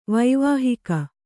♪ vaivāhika